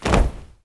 AFROFEET 6-L.wav